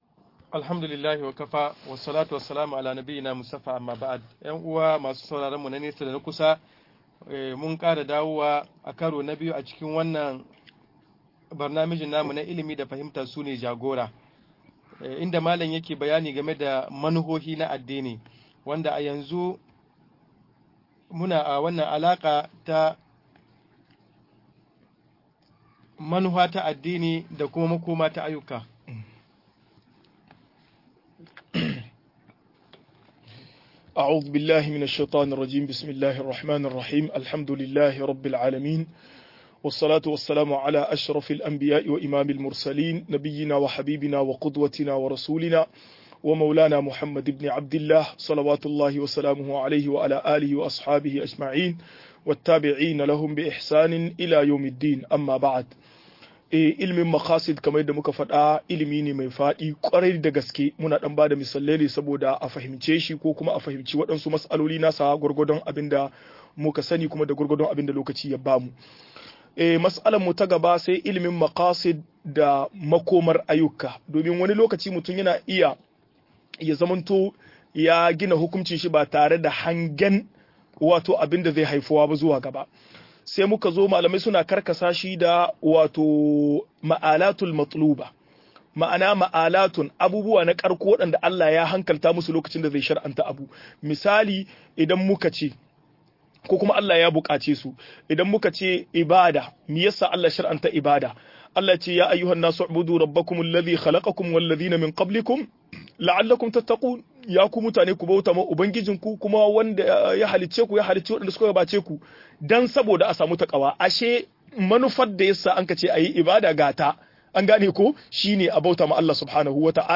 Maƙasid Asshari'ah-04 - MUHADARA